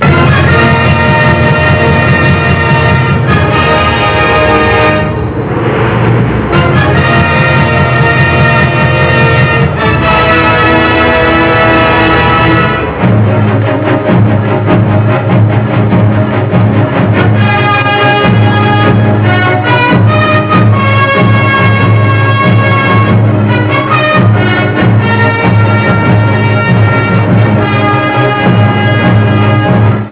magniloquentemente avventurose.
Original track music